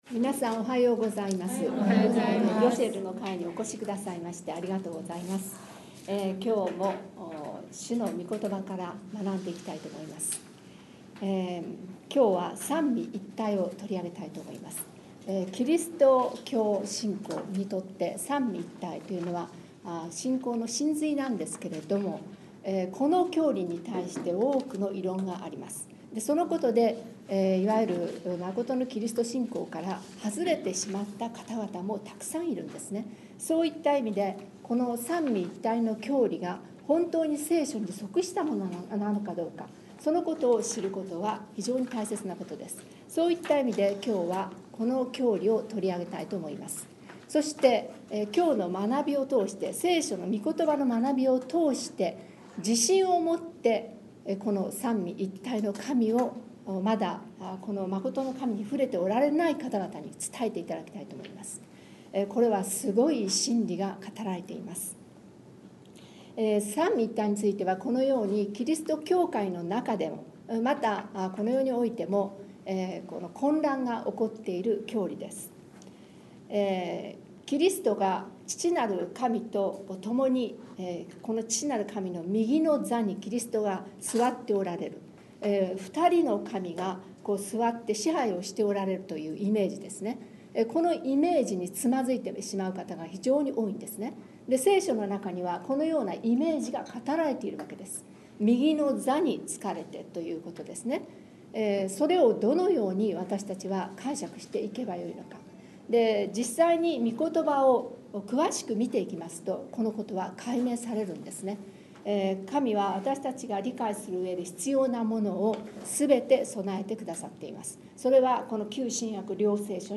9月8日に第11回ヨシェルの会が開催されました。